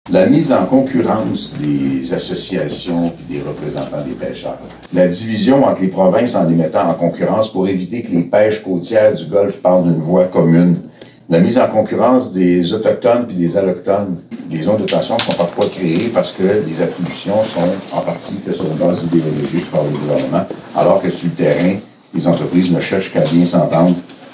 Habitué de la pointe gaspésienne, Yves-François Blanchet s’est présenté devant les médias accompagné d’une vingtaine de pêcheurs – principalement des homardiers – afin de faire part de ses constats en lien avec l’industrie.